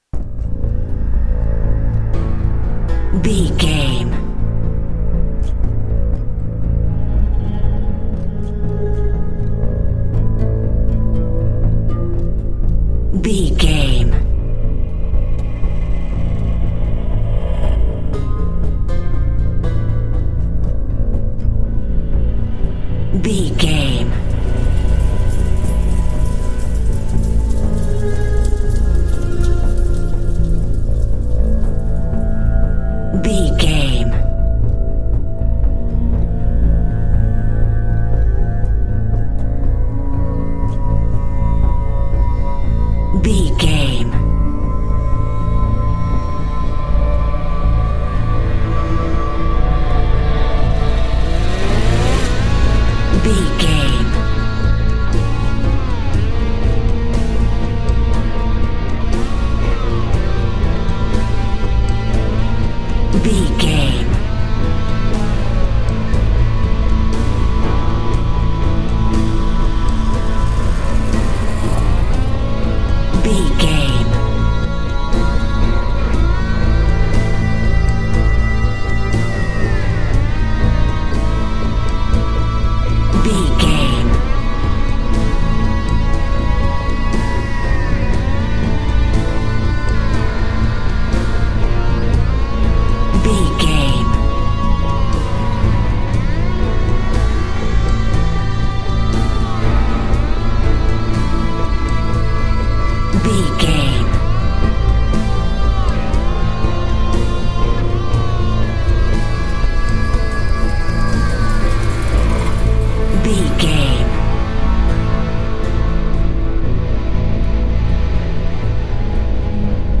Thriller
Aeolian/Minor
Slow
piano
synthesiser
electric guitar
ominous
dark
suspense
haunting
creepy